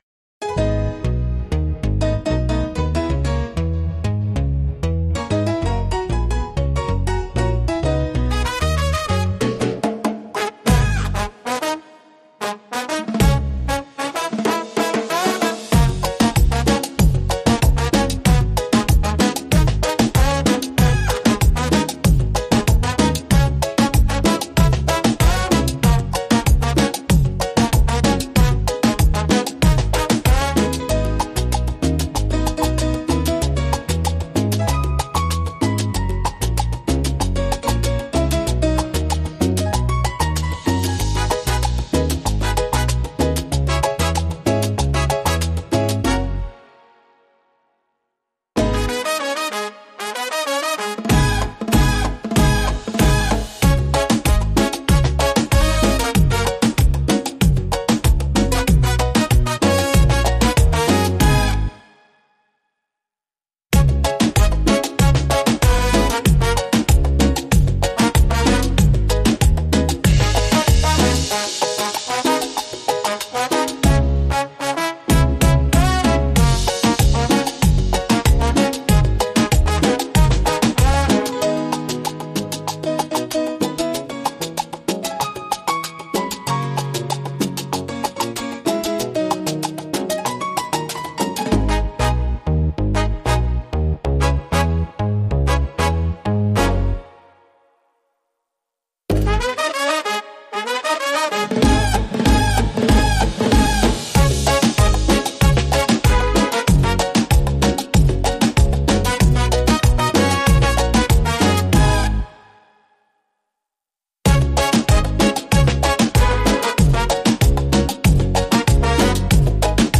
Beat Reggaeton Instrumental
• Mix e mastering di qualità studio
G#m